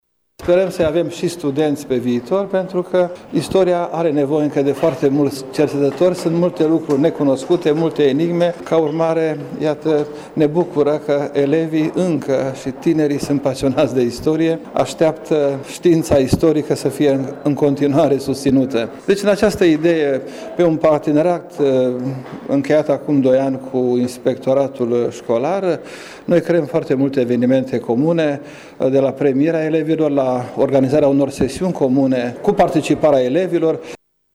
Festivitatea a fost găzduită, azi, de Aula Universității „Petru Maior” din Tg.Mureș.